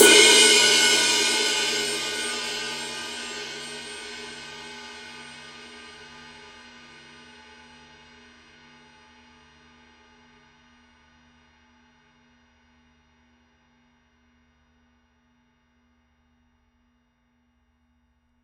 Тарілка райд 24"
RUDE continues to be the leading choice of sound for raw, merciless and powerful musical energy in Rock, Metal, and Punk.